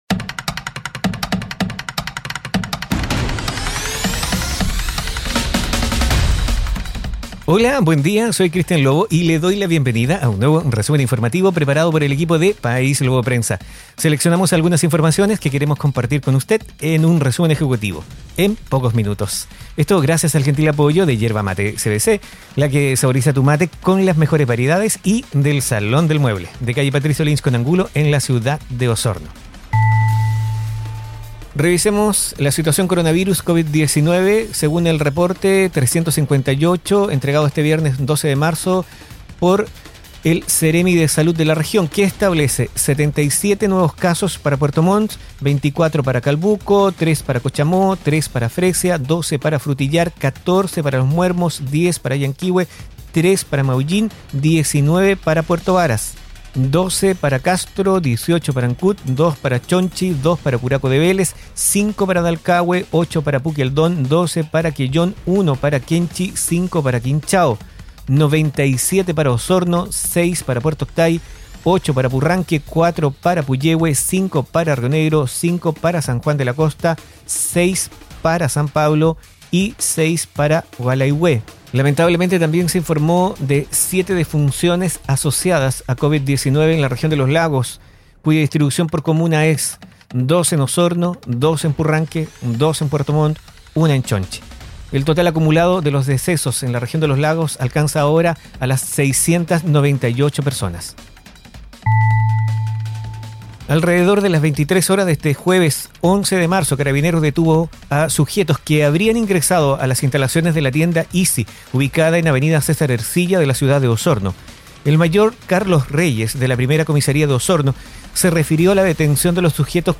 Informaciones enfocadas en la Región de Los Lagos. Difundido en radios asociadas.